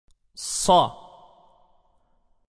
1. Tabii Med (Medd-i Tabii):
Tabii med hareke uzunluğunun iki katı uzatılır.